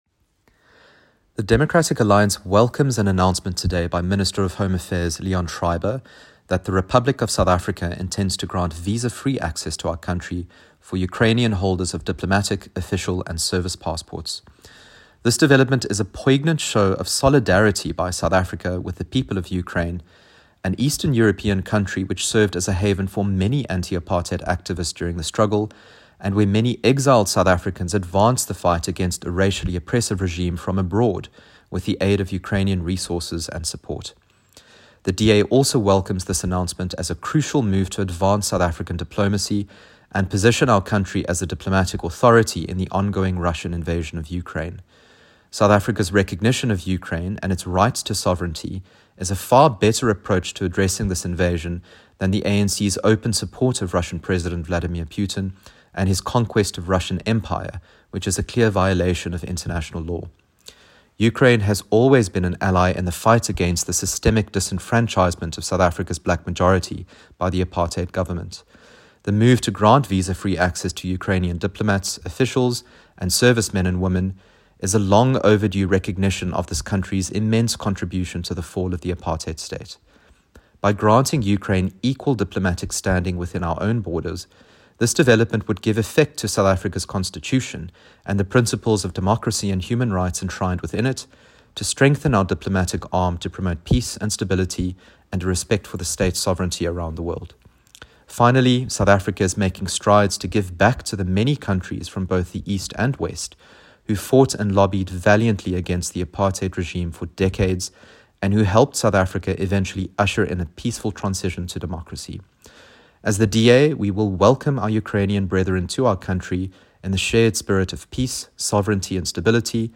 soundbite by Ryan Smith MP.